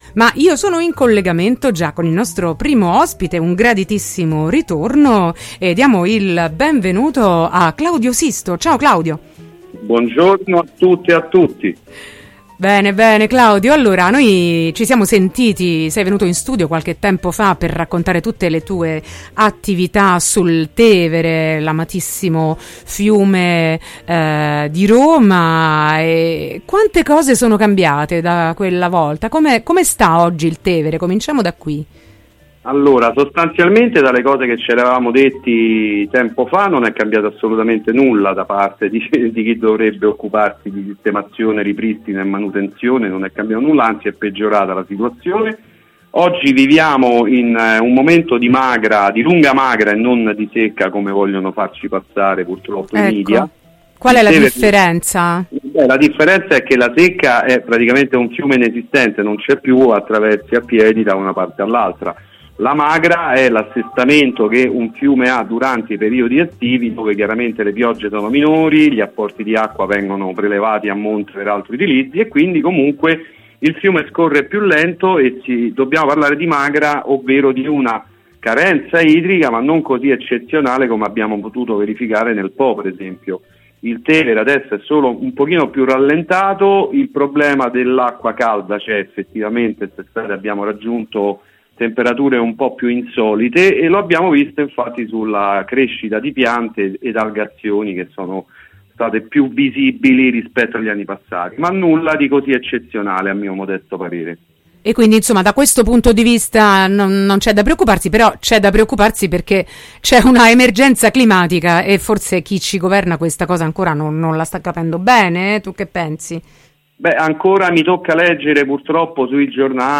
Il Tevere e l’ambiente da salvare: intervista
intervista-mondosommerso-16-9-22.mp3